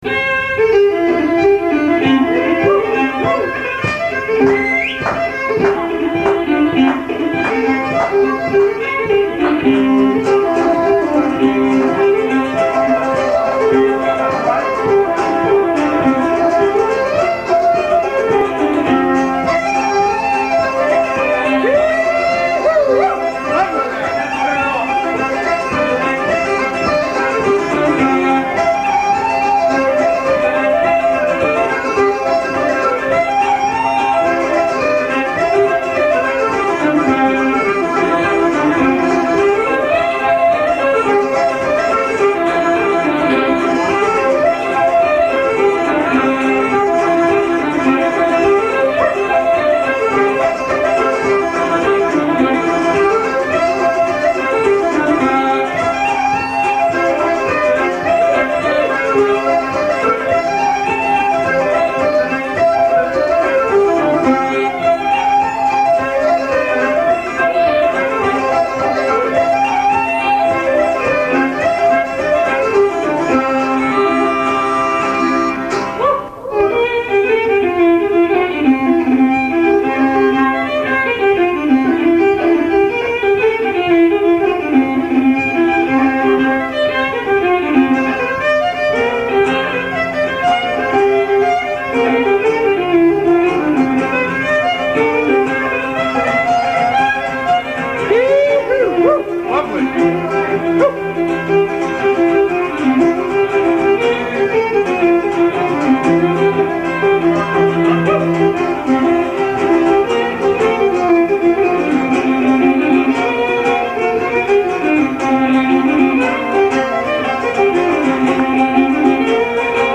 Concert/ceili recorded live at Concannon's, Norwood MA
fiddle
flute
pipes
guitar/bouzouki
slip jig